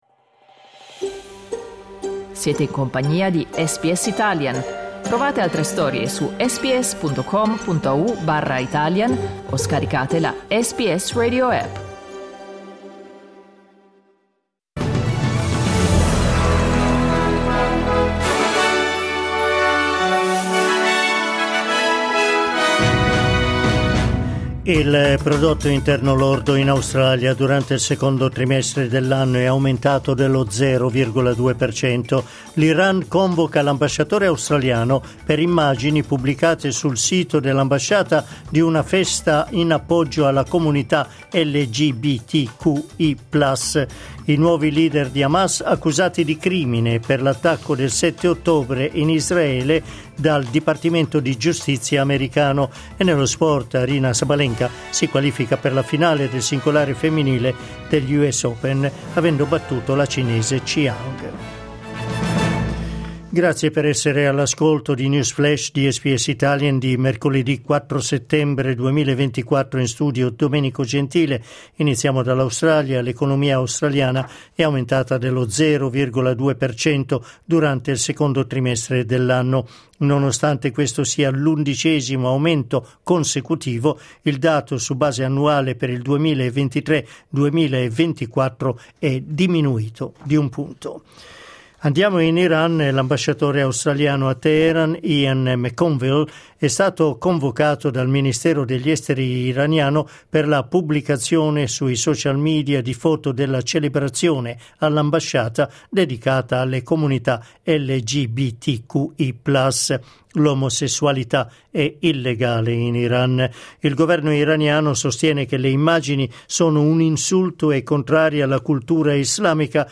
News flash mercoledì 4 settembre 2024